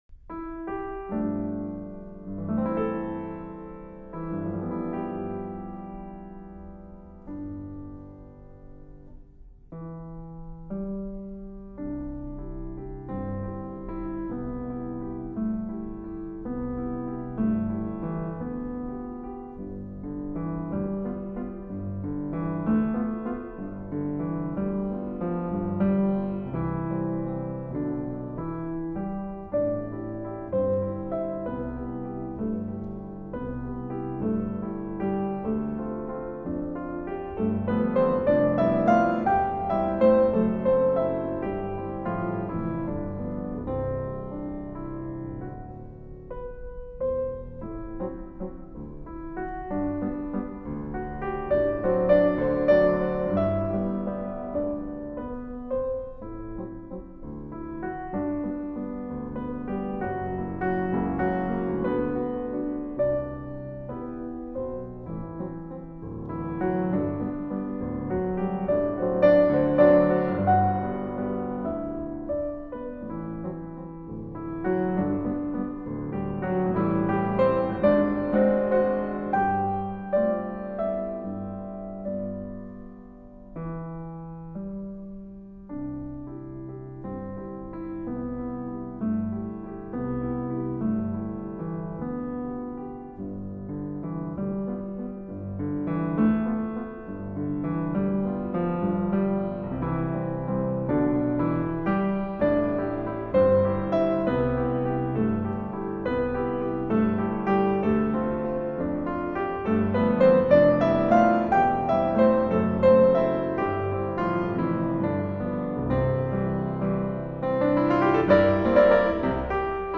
音乐类型：Classical